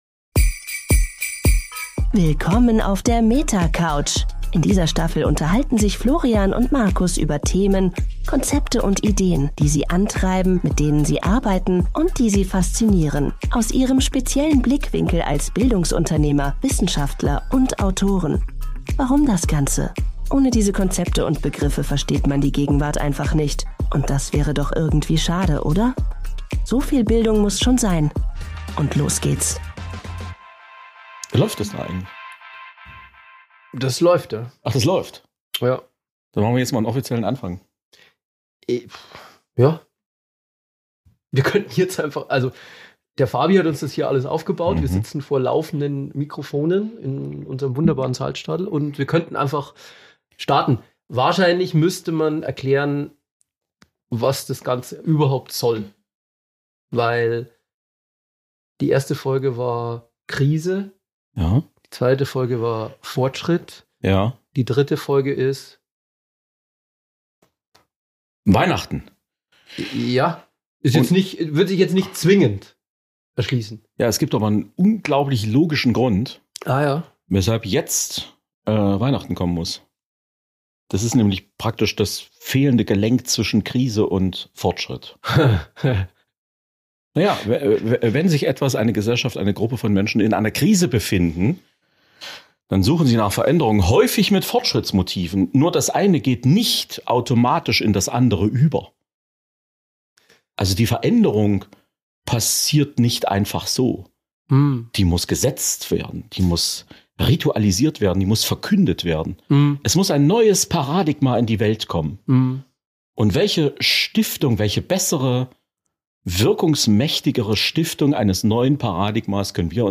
Ein Gespräch über Rituale, die Angst vor der Leere – und die Frage, wie ein Fest aussehen könnte, das wir wirklich verdient haben.